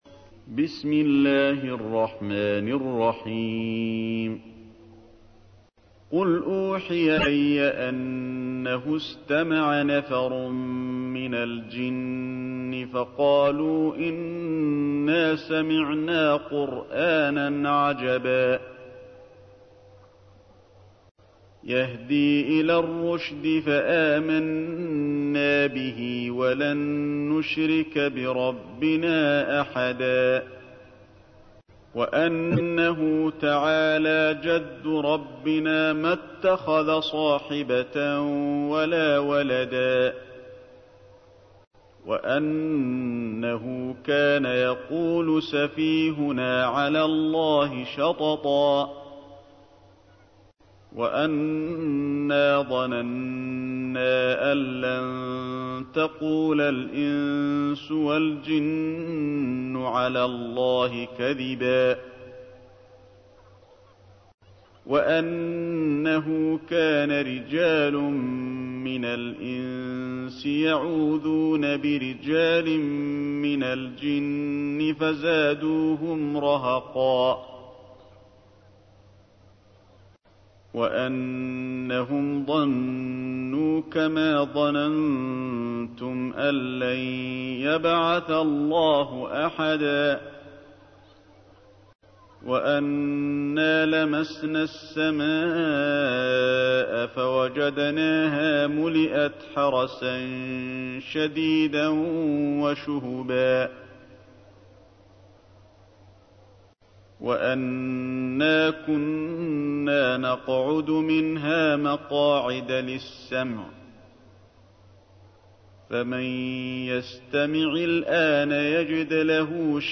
تحميل : 72. سورة الجن / القارئ علي الحذيفي / القرآن الكريم / موقع يا حسين